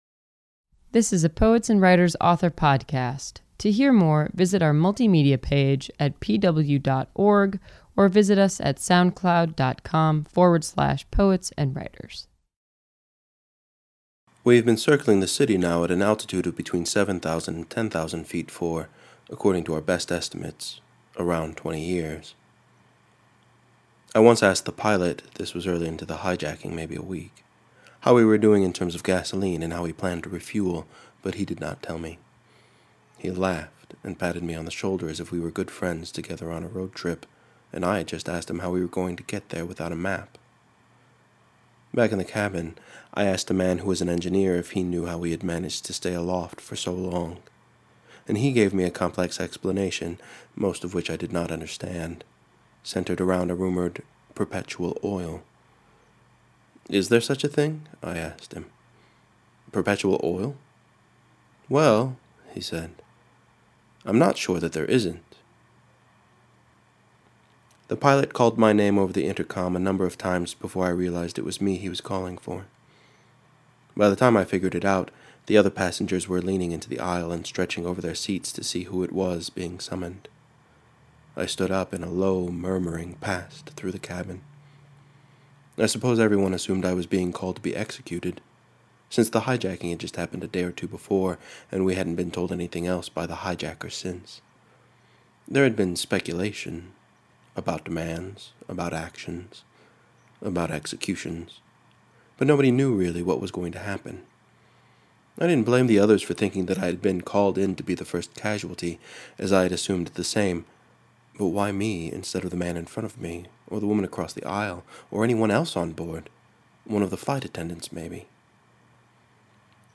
Fiction writer